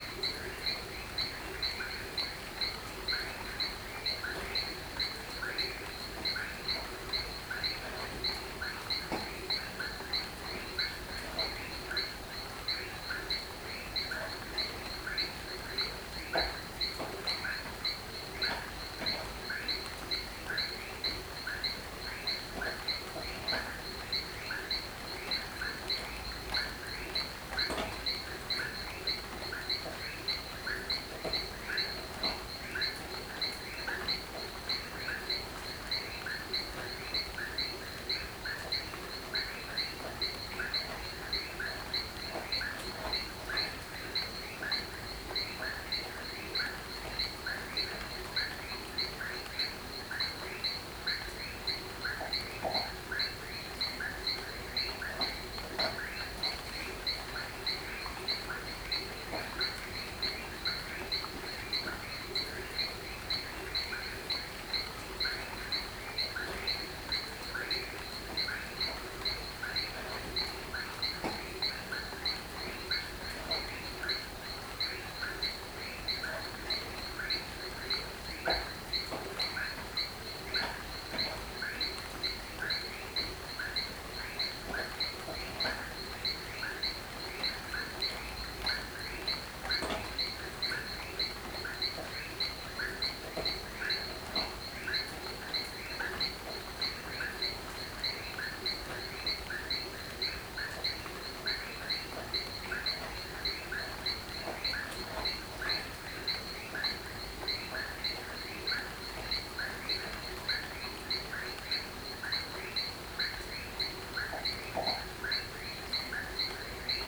CSC-09-030-LE - Chuva com sapos a noite gravacao dentro do quarto, batidas.wav